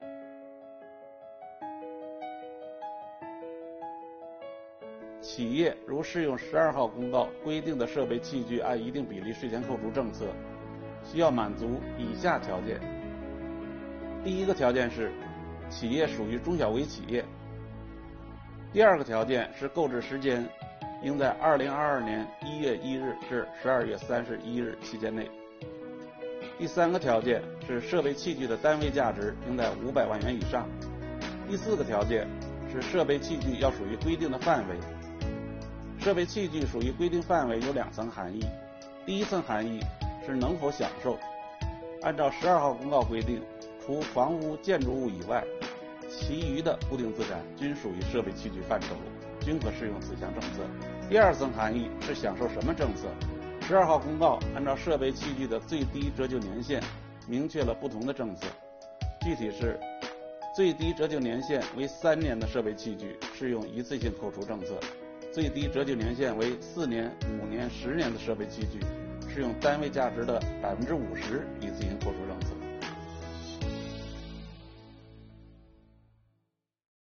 本期课程由国家税务总局所得税司一级巡视员刘宝柱担任主讲人，解读中小微企业购置设备器具按一定比例一次性税前扣除政策。今天，我们一起学习：中小微企业购置设备器具按一定比例一次性税前扣除政策适用条件有哪些？